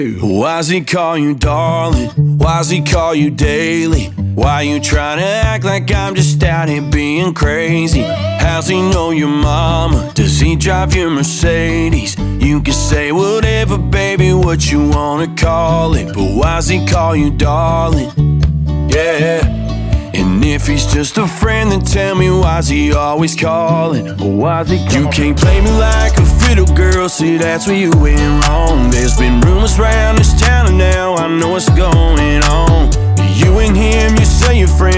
2024-02-02 Жанр: Кантри Длительность